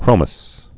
(krōməs)